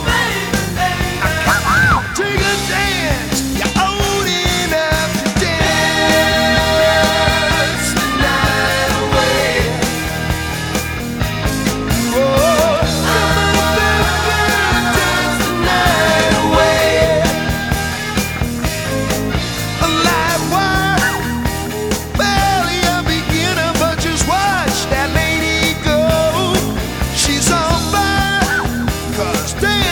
• Hard Rock